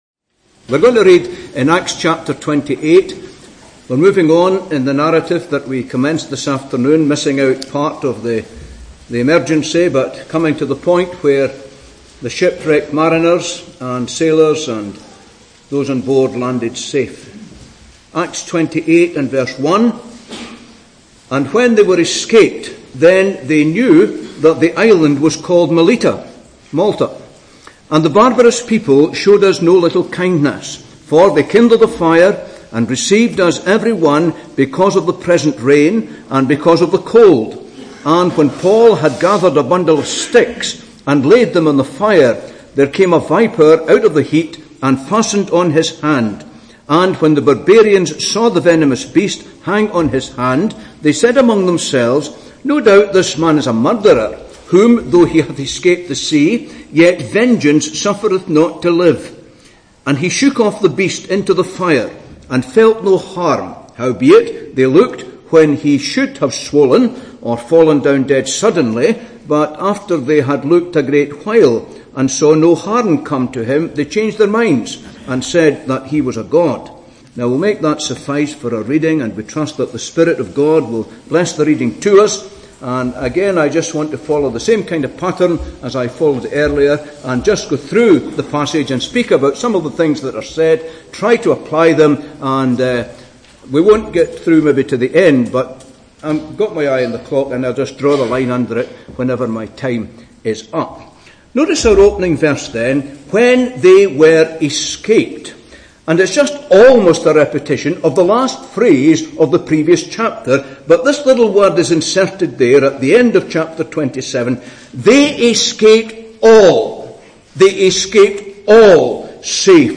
Easter Conference 2017